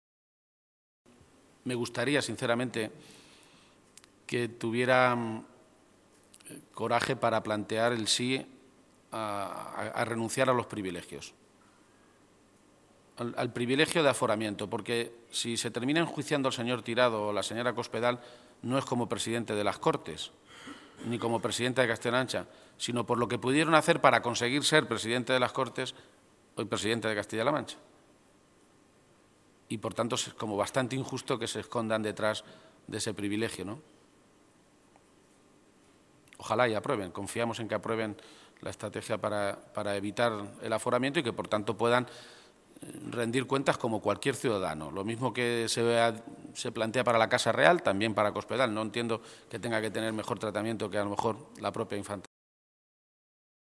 García-Page se pronunciaba de esta manera esta mañana, en Toledo, a preguntas de los medios de comunicación en una comparecencia que se producía pocas horas antes de que el Pleno de la Asamblea autonómica, esta tarde, a partir de las cuatro, debata y vote esa iniciativa socialista.
Cortes de audio de la rueda de prensa